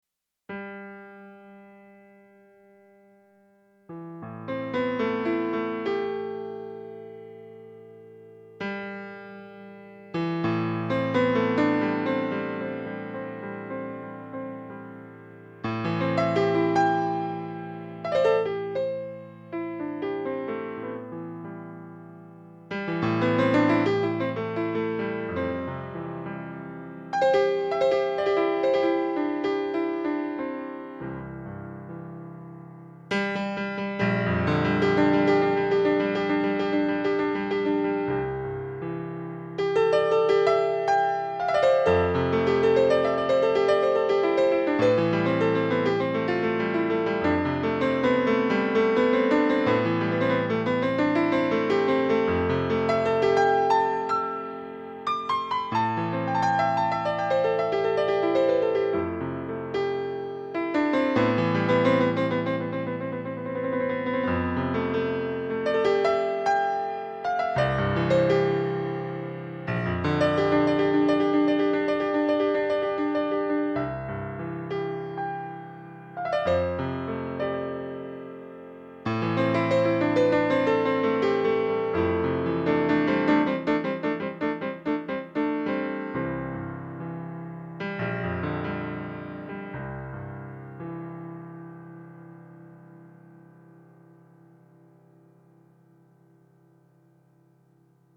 These improvisations are just musical ideas and expressions all created in the moment, a stream of consciousness. The keyboard used is a controller so the musical response quality is limited to the nature of the animal.